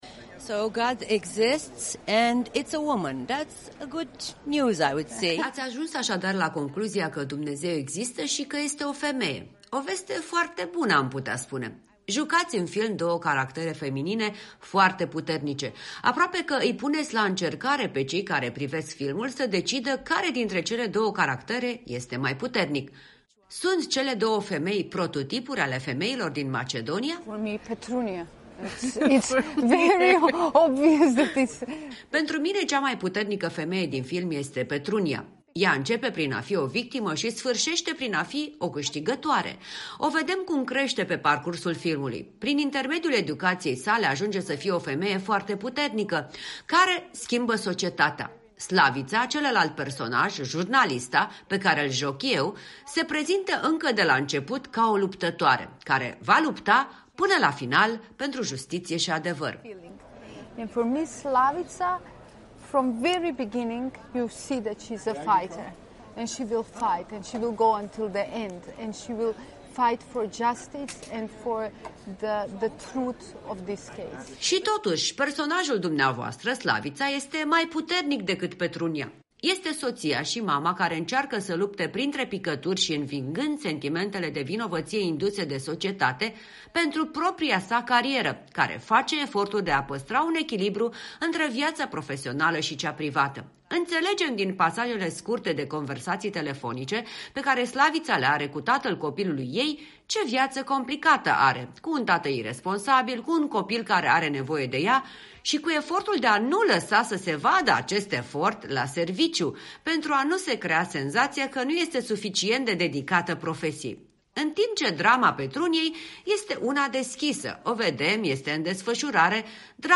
Aceste modele se schimbă prin educație”, spun într-un interviu cu Europa Liberă protagonistele peliculei câștigătoare, Labina Mitevska și Zorica Nusheva.